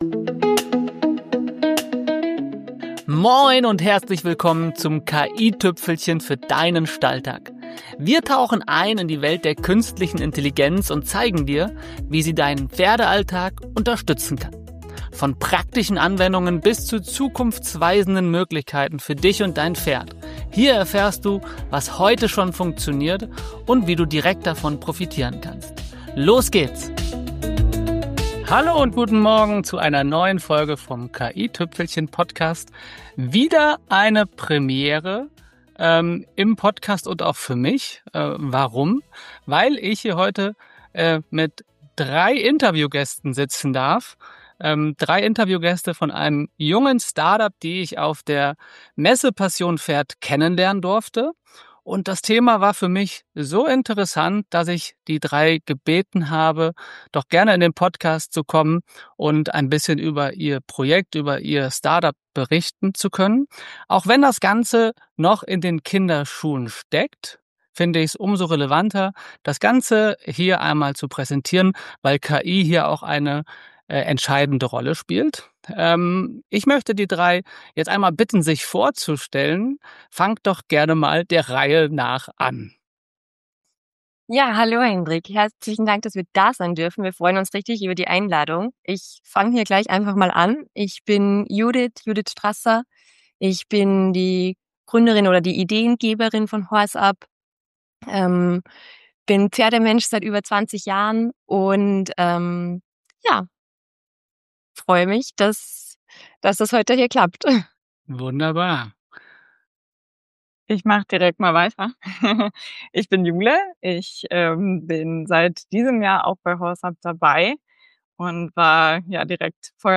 Beschreibung vor 3 Monaten Drei Gründer:innen, eine Vision: eine smarte, kollaborative Gesundheitsakte fürs Pferd. In dieser Interviewfolge spreche ich mit dem Startup HorseUp über ihre Idee ihrer digitalen Pferdegesundheitsakte, die Daten bündelt, verständlich aufbereitet und perspektivisch mit KI analysiert. Wir sprechen über echte Probleme aus der Praxis, Datenschutz, Identifikation von Pferden, den Einsatz von KI (z. B. Voice-to-Text, Dokumenten-Analyse) und darüber, warum ganzheitliche Pferdegesundheit ohne Daten-Sharing kaum möglich ist.